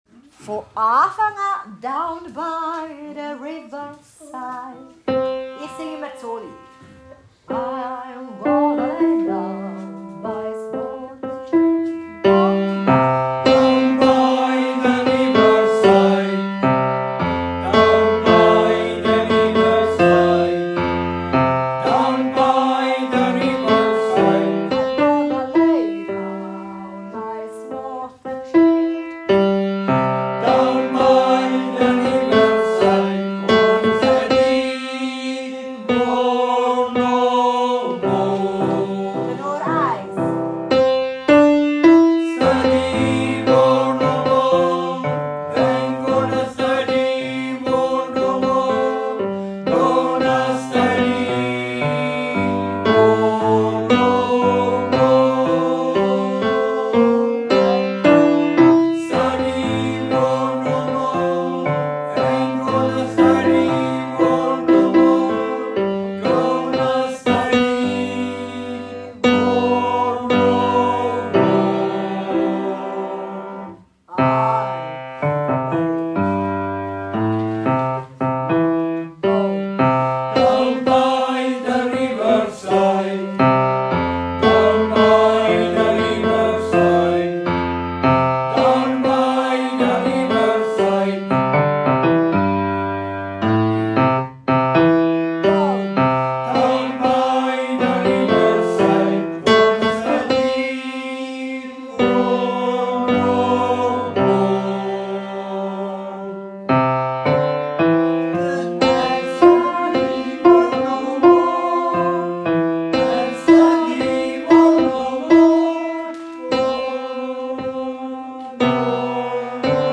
2. Tenor